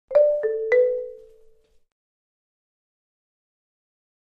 Marimba, Accent, Short Happy Signals, Type 4